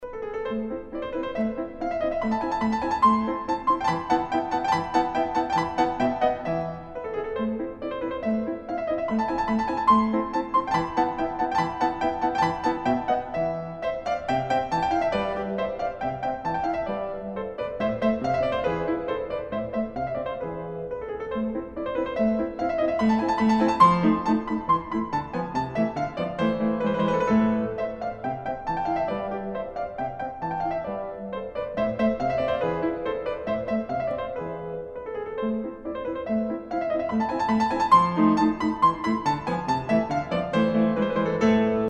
классические